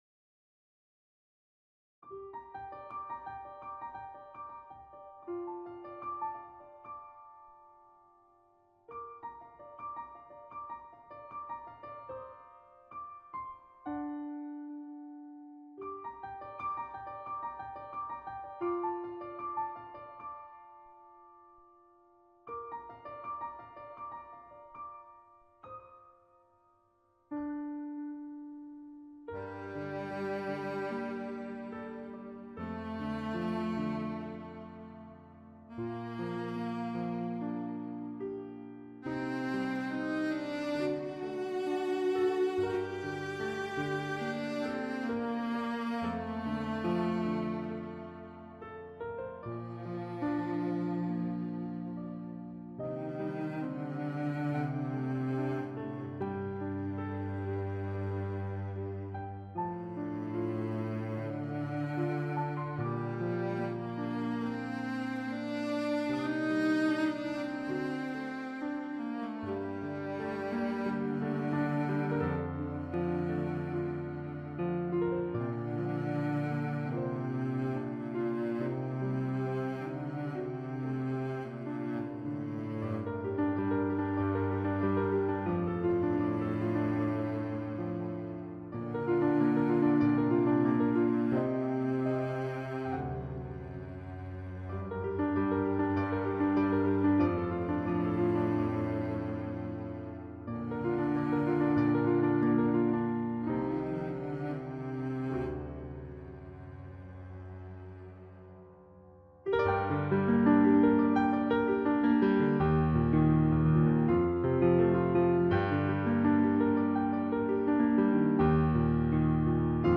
1 Stunde Cello - Violin